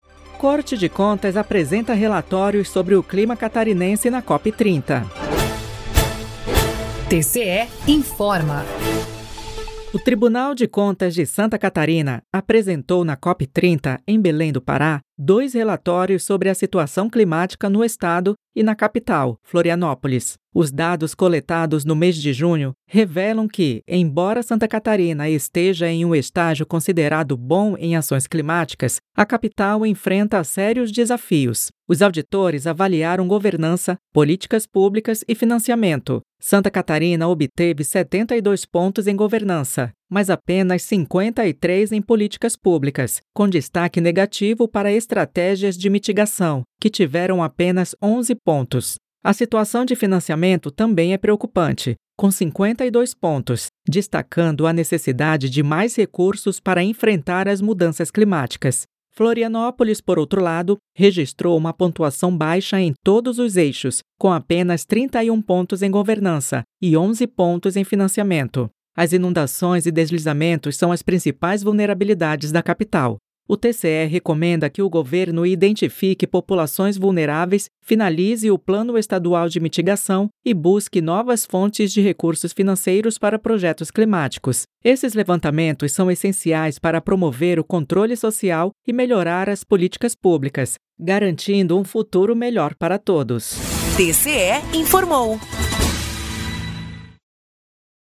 VINHETA TCE INFORMOU